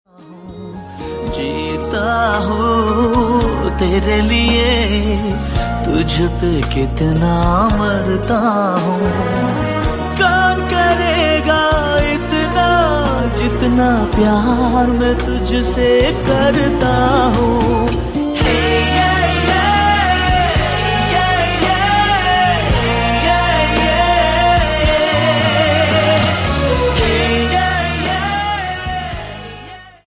Bollywood & Indian